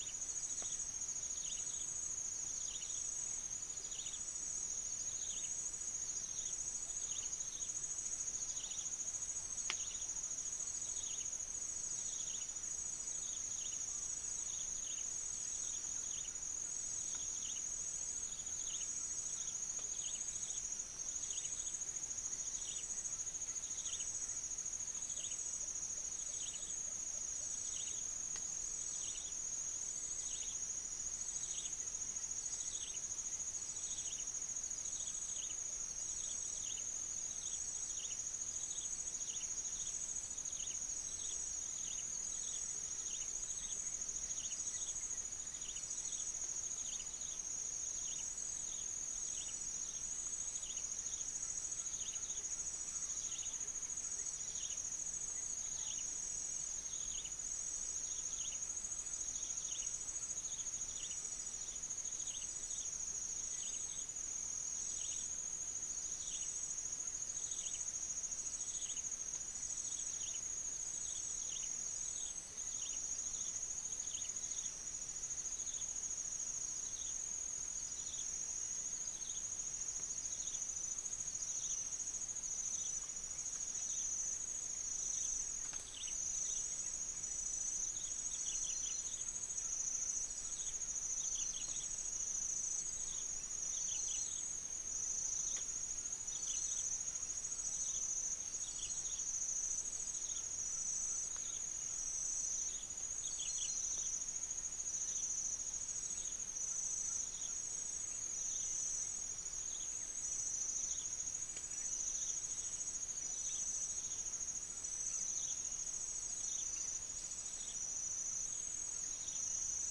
Hydrornis guajanus 3156 | Pycnonotus goiavier 1982 | Cymbirhynchus macrorhynchos
unknown bird